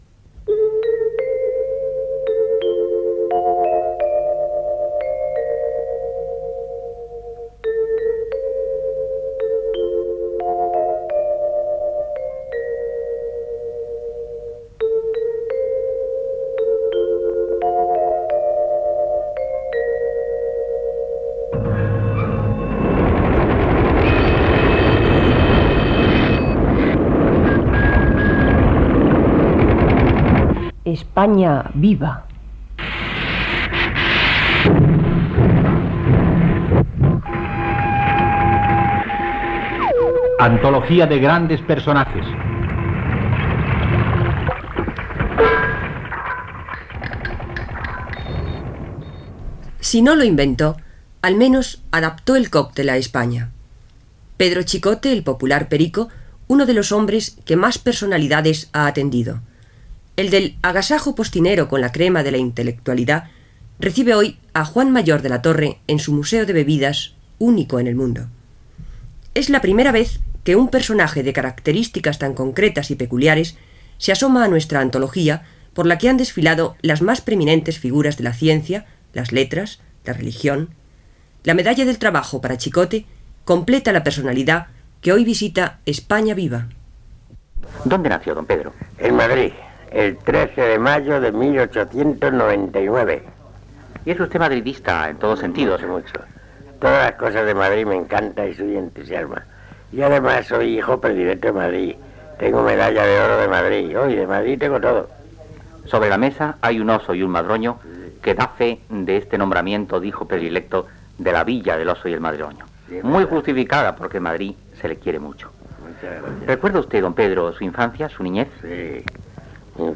Sintonia de la cadena, careta del programa i entrevista al barman Pedro "Perico" Chicote , al seu local de la Gran Vía de Madrid